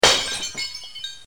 breakingglass2.ogg